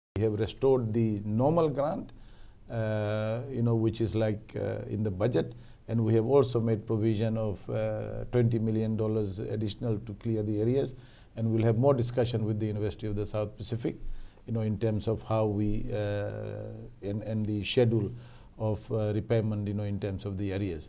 In an exclusive interview on FBC TV program “Saqamoli Matters”, Finance Minister Professor Biman Prasad also cleared the air on his role on the USP council and matters concerning the management of USP.
Finance Minister Professor Biman Prasad